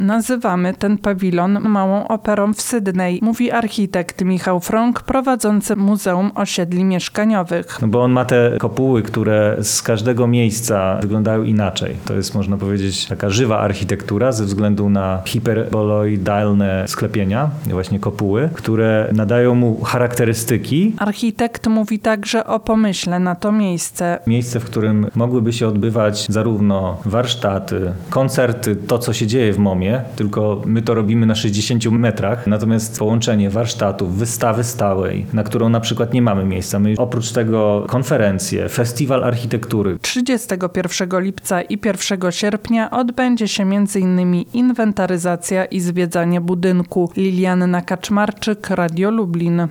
O szczegółach nasza reporterka.